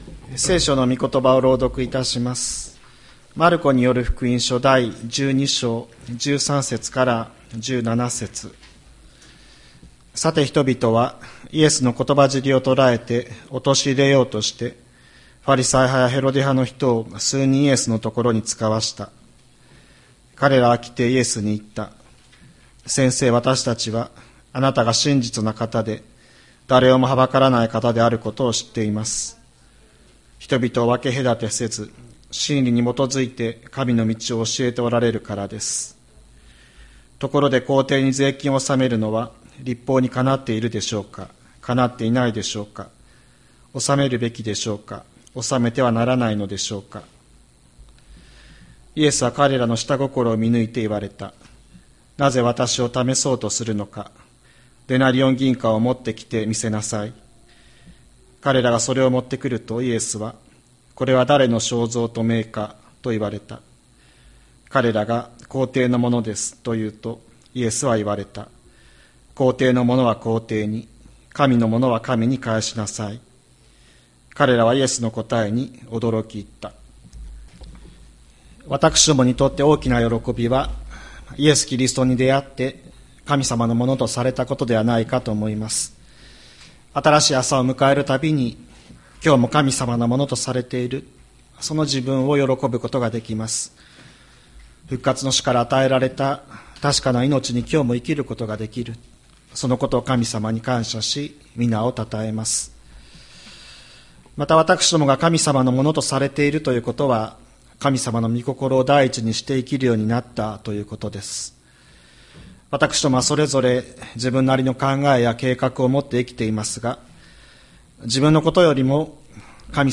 2025年02月09日朝の礼拝「神にお返ししよう」吹田市千里山のキリスト教会
千里山教会 2025年02月09日の礼拝メッセージ。